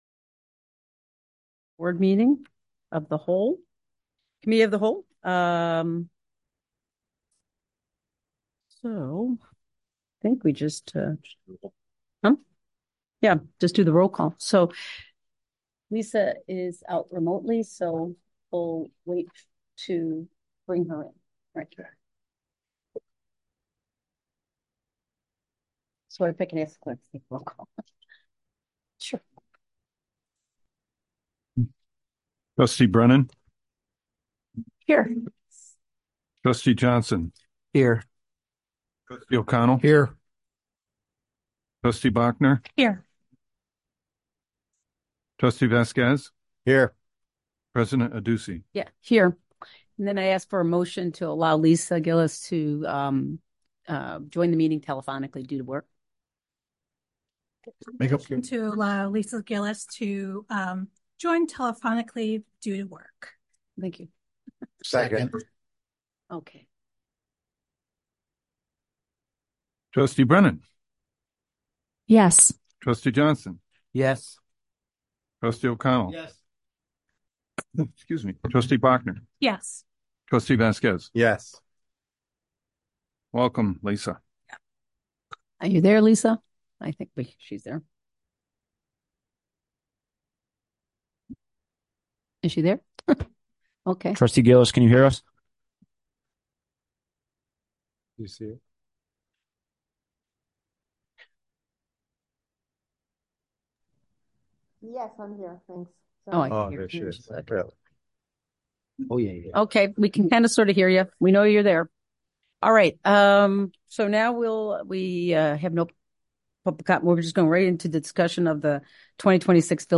Special Committee of the Whole Meeting
Village Hall - 1st Floor - COMMUNITY ROOM - 400 Park Avenue - River Forest - IL